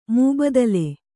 ♪ mūbadale